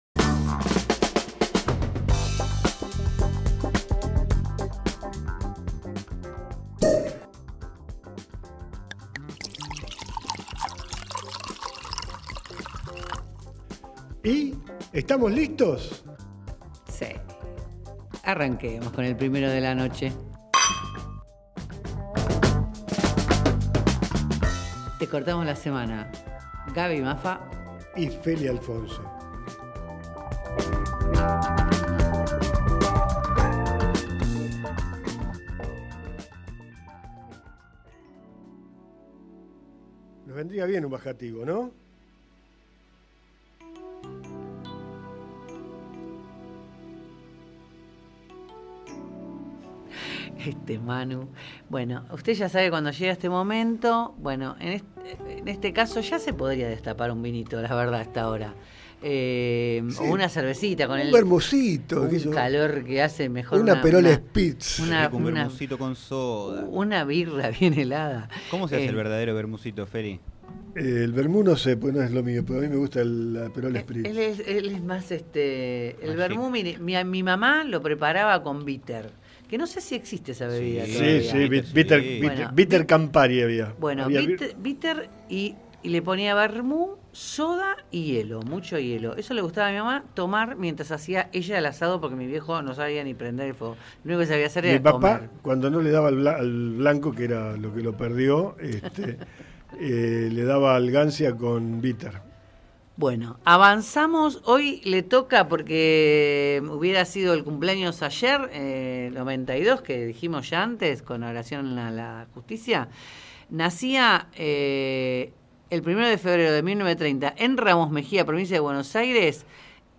Recordamos a María Elena Walsh en su cumpleaños 92. Te contamos algunos aspectos de su vida y de su obra y te leemos algunos poemas poco conocidos, ya que siempre volvemos a sus canciones infantiles.